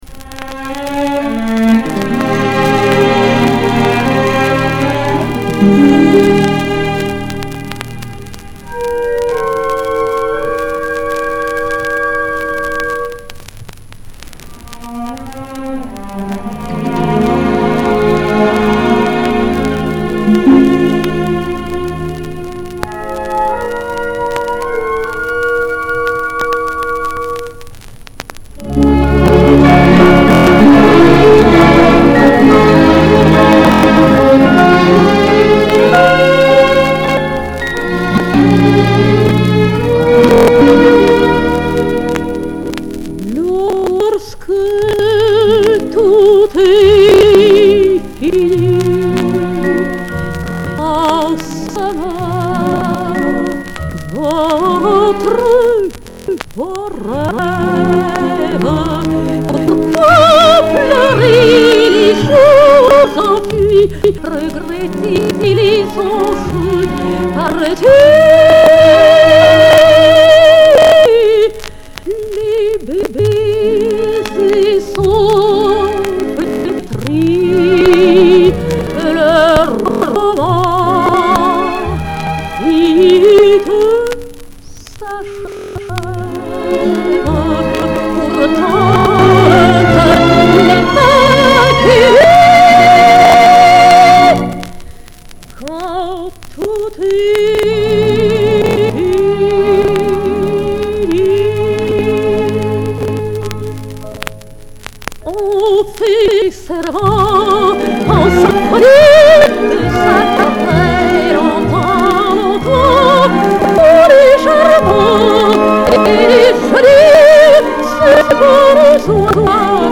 Н.А.Обухова- Вальс О.Кремье "Когда умирает любовь" ( фр.яз)